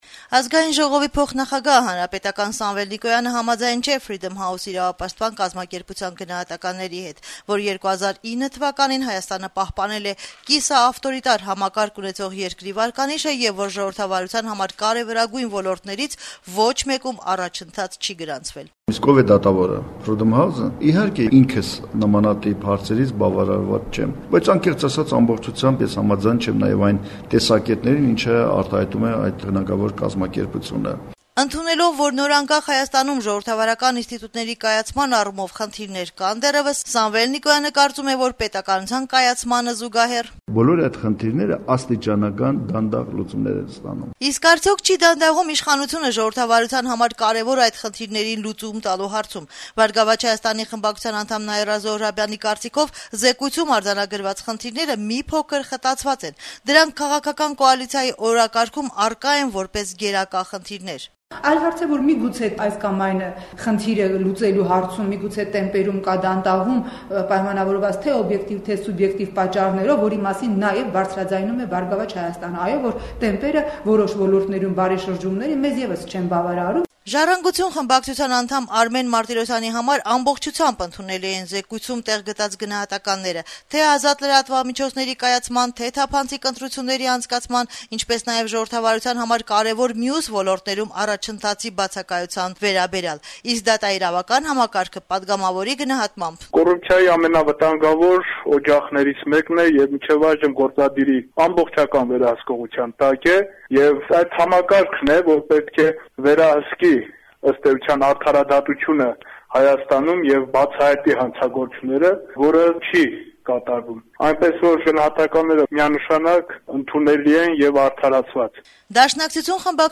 Հայաստանի Ազգային ժողովում ներկայացված քաղաքական ուժերի ներկայացուցիչները չորեքշաբթի օրը «Ազատություն» ռադիոկայանի հետ զրույցներում իրենց կարծիքները հայտնեցին Freedom House միջազգային իրավապաշտպան կազմակերպության վերջին զեկույցի առնչությամբ: